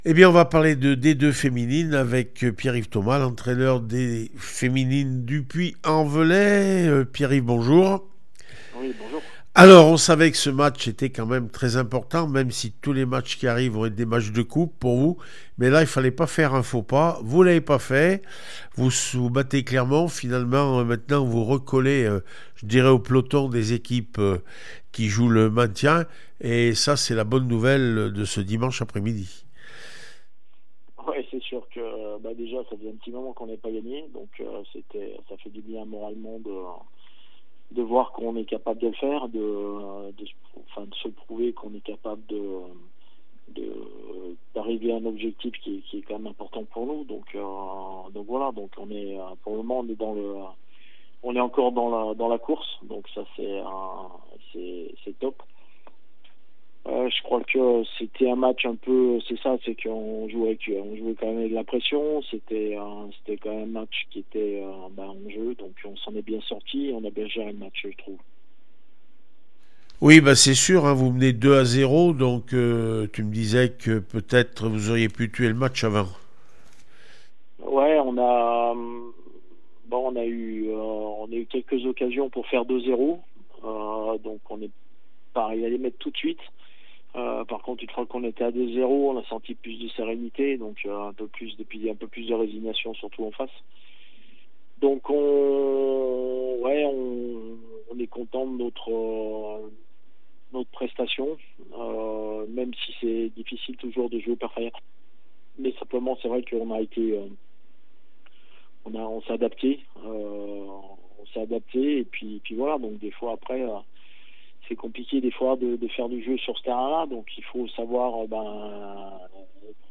13 mars 2023   1 - Sport, 1 - Vos interviews